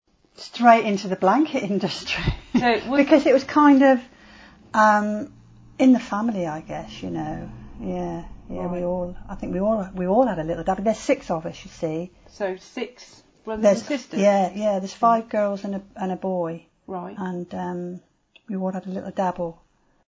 a former worker from the Witney blanket industry